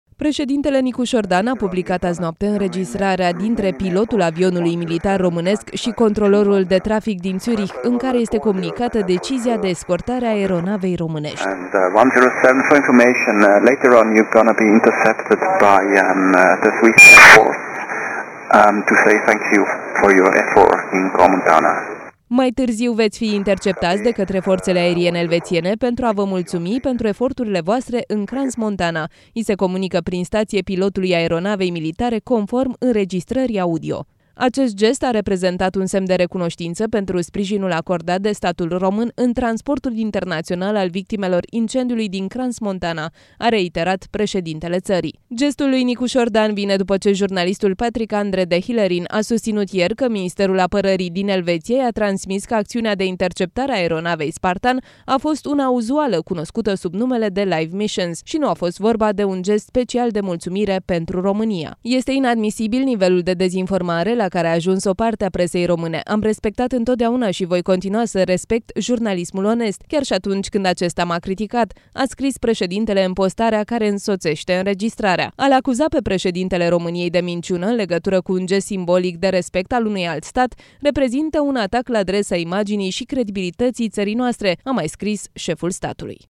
Nicuşor Dan a publicat în noaptea de joi spre vineri înregistrarea convorbirii, în care se aude cum partea elveţiană transmite mulţumiri pentru sprijinul acordat de România în cazul incendiului din Crans-Montana.
„Puteţi asculta înregistrarea convorbirii dintre pilotul român al aeronavei Spartan şi controlorul de trafic din Zurich, în care este comunicată decizia de escortare a aeronavei româneşti.